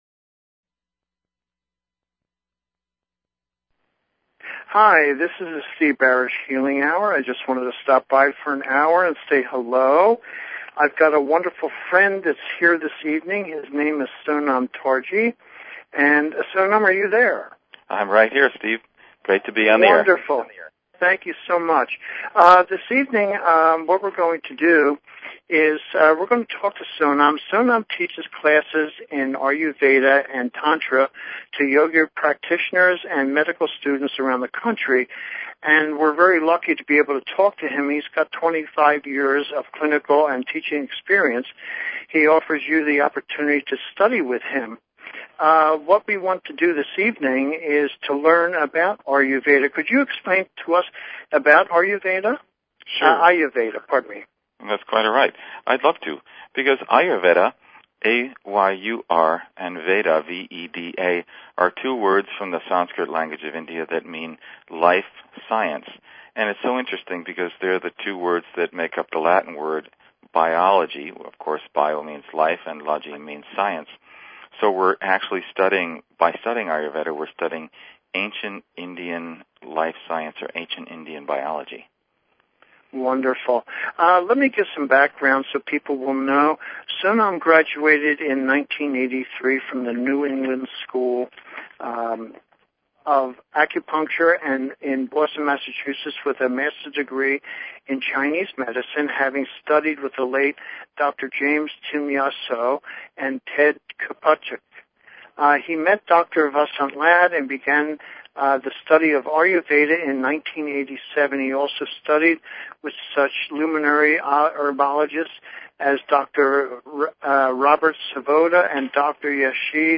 Talk Show Episode, Audio Podcast, The_Healing_Hour and Courtesy of BBS Radio on , show guests , about , categorized as